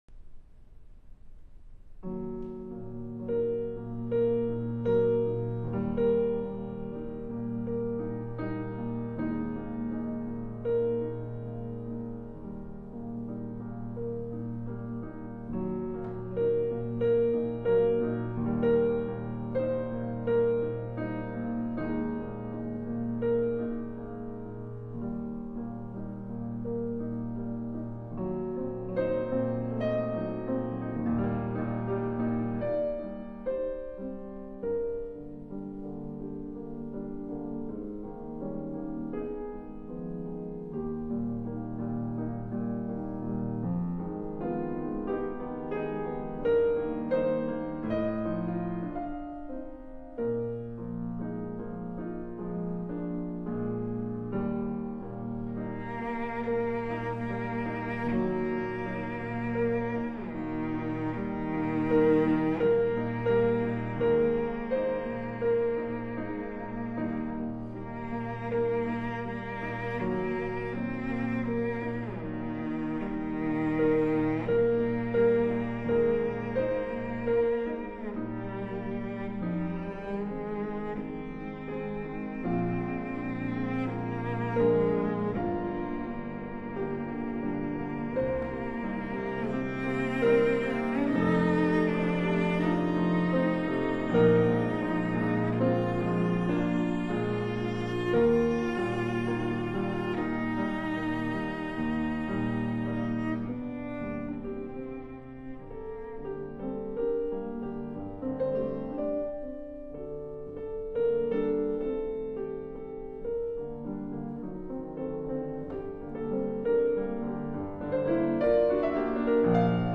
Cello Sonata